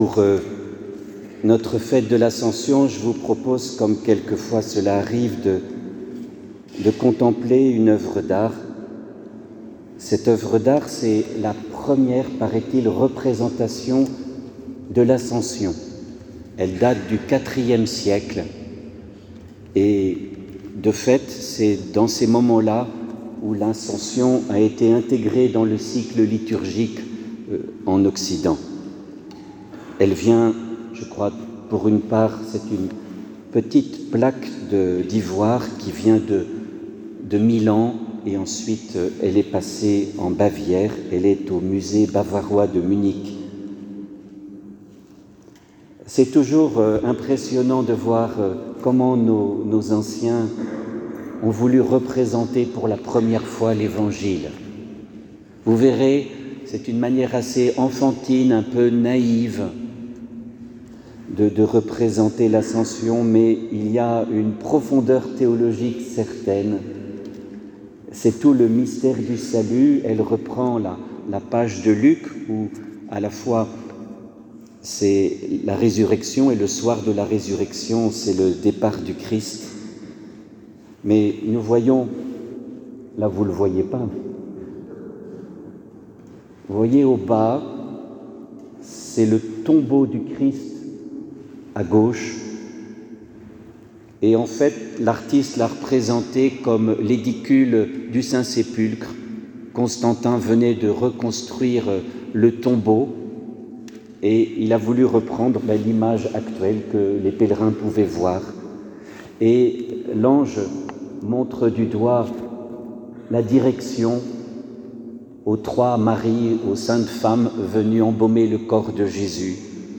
Homelie-Ascension-_1_.mp3